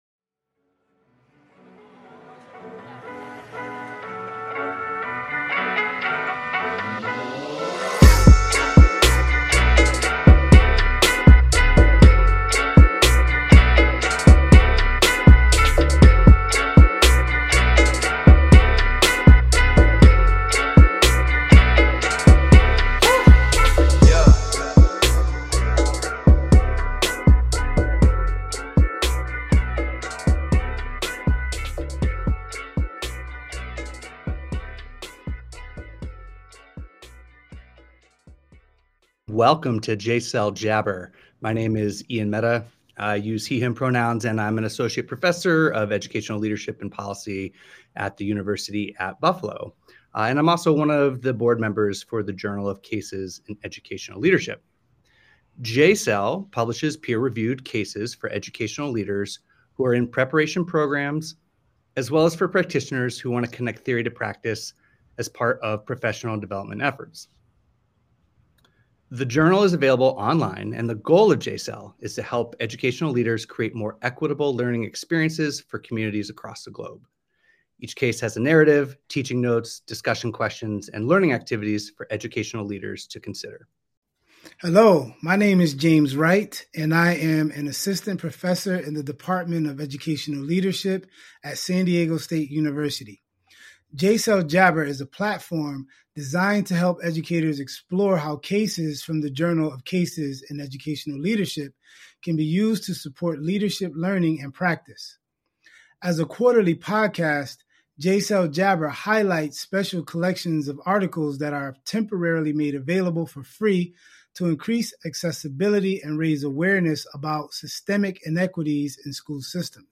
Interview with Dr. Gholdy Muhammad
In this episode we interview Dr. Gholdy Muhammad.